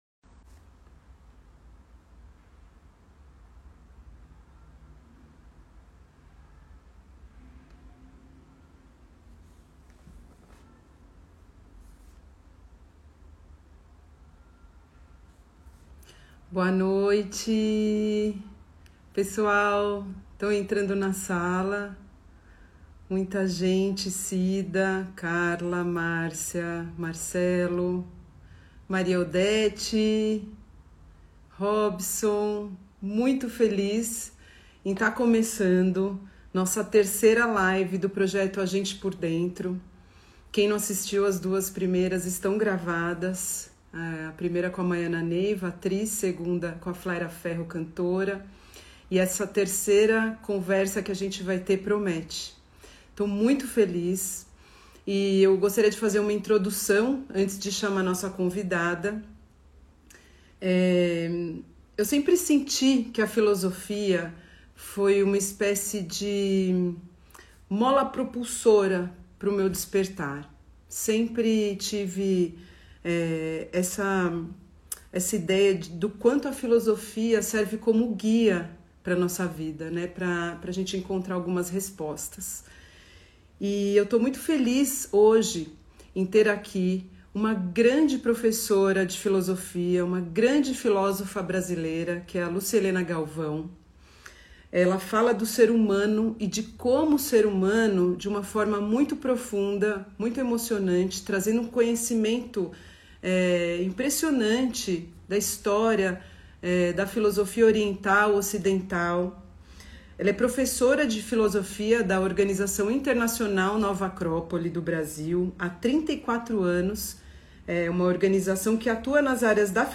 Live completa